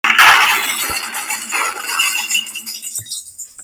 Computer Breaking Sound Button - Free Download & Play